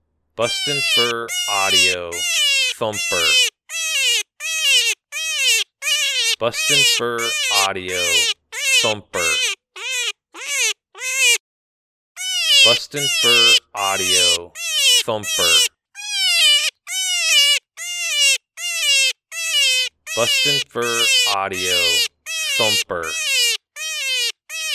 Adult Cottontail in distress. Excellent sound for calling predators, year around.